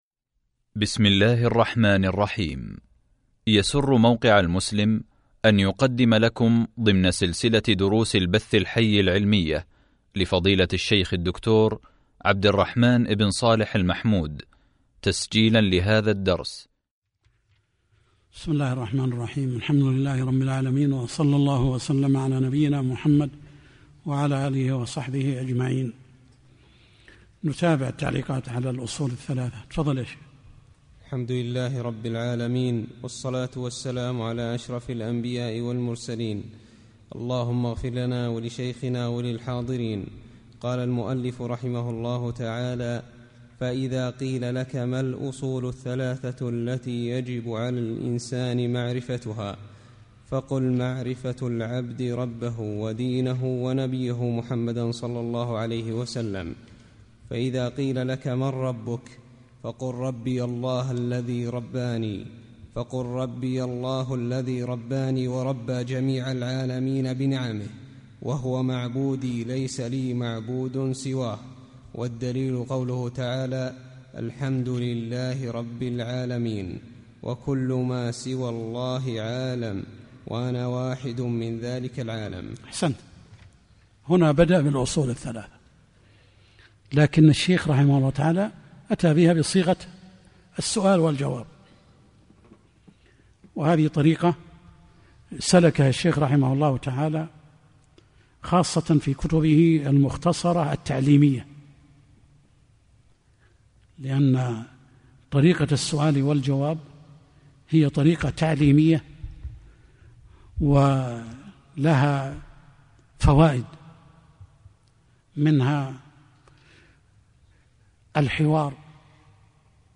شرح الأصول الثلاثة | الدرس 6 | موقع المسلم